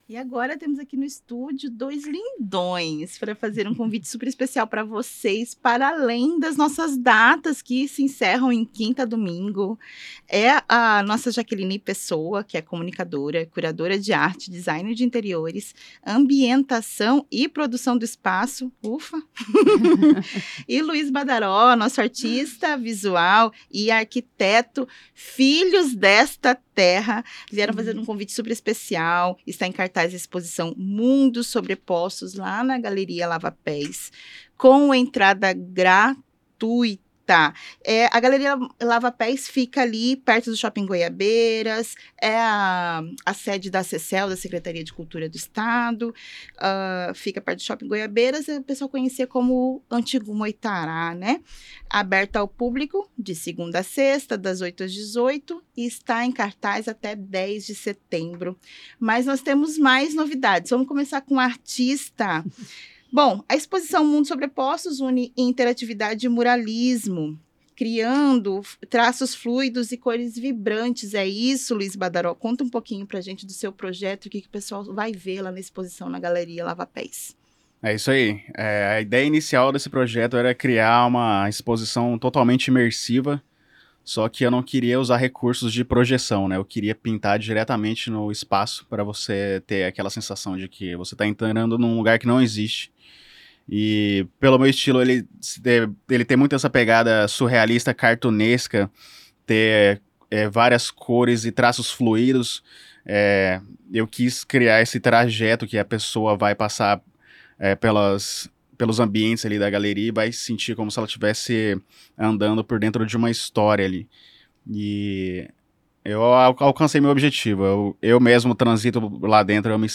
Expresso 89 Entrevista